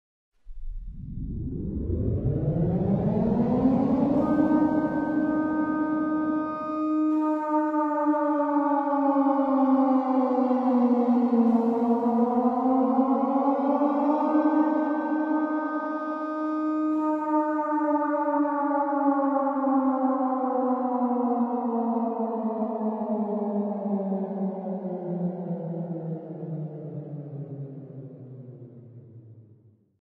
terrifying-air-raid-siren-sound.mp3